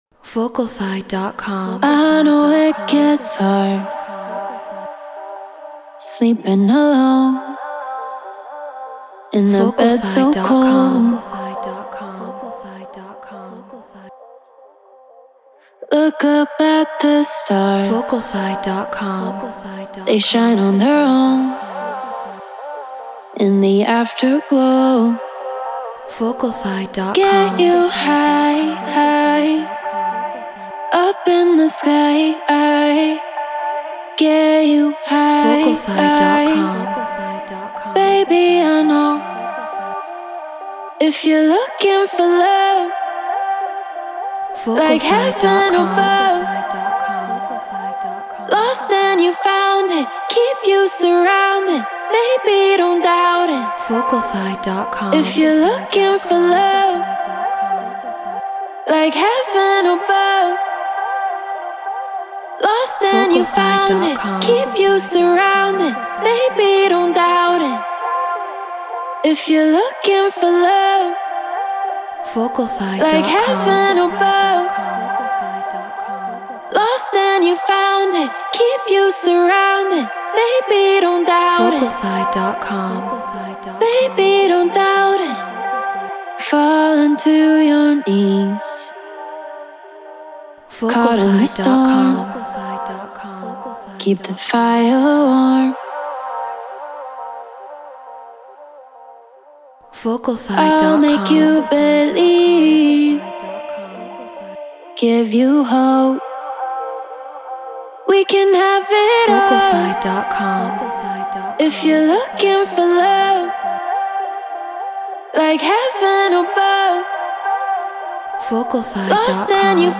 Trance 136 BPM Cmin
Shure KSM 44 Apollo Twin X Pro Tools Treated Room